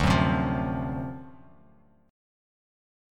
D7#9 Chord
Listen to D7#9 strummed